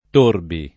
Torbi [ t 1 rbi ]